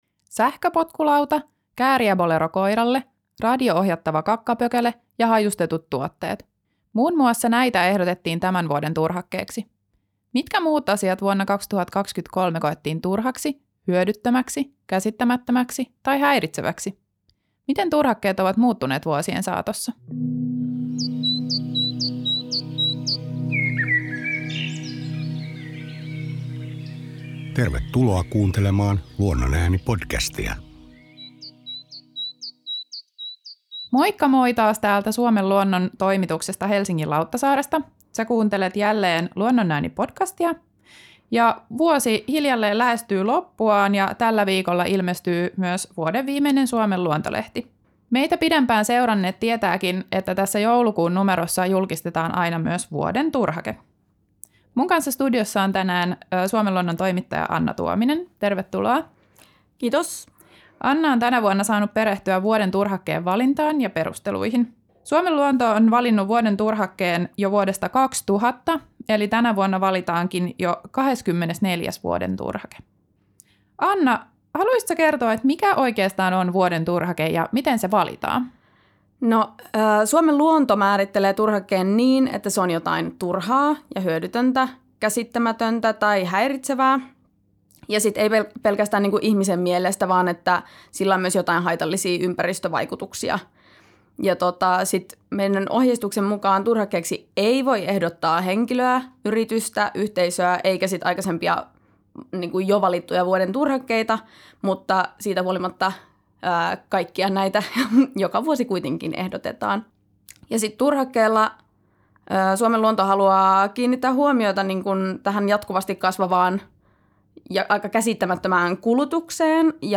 Podcastissa omituisista, kamalista ja hauskoista turhakkeista keskustelemassa